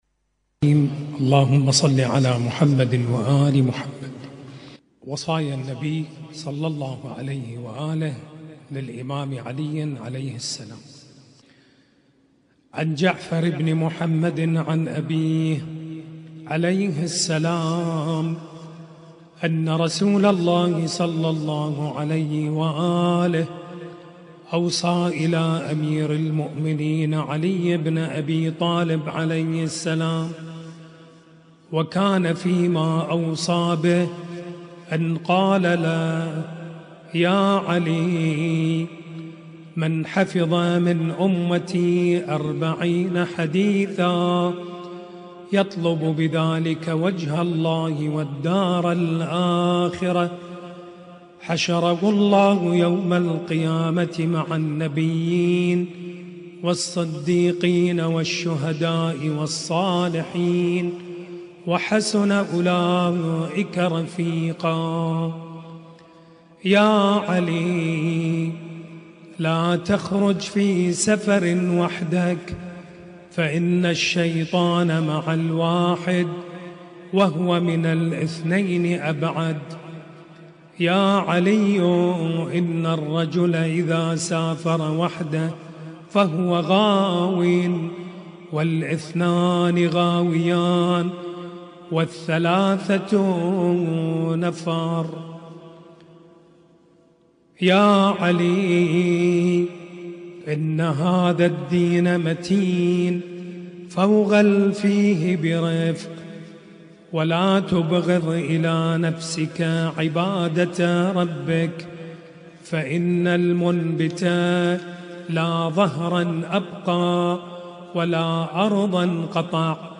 ليلة 7 محرم